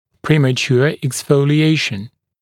[ˌpremə’tjuə eksˌfəulɪ’eɪʃn][ˌпрэмэ’тйуэ эксˌфоули’эйшн]преждевременное выпадение (молочного зуба)